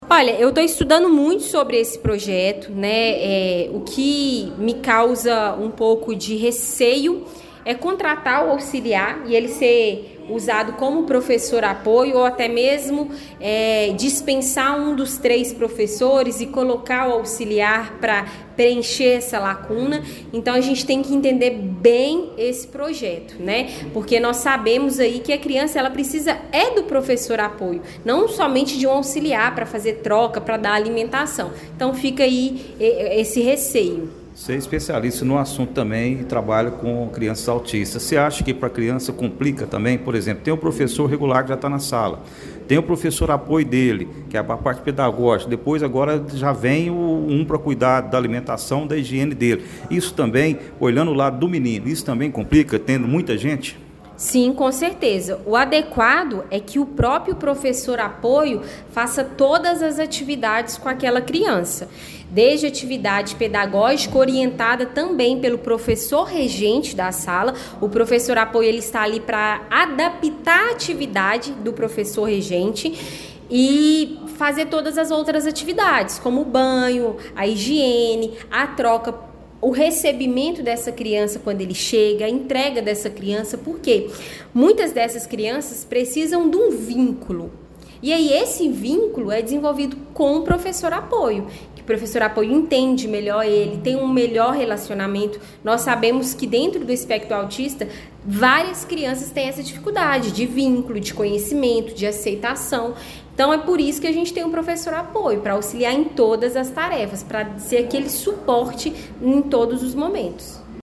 A qualidade do ensino e a infraestrutura das instituições municipais de Pará de Minas foram o centro das atenções durante reunião da Câmara Municipal, realizada ontem (07).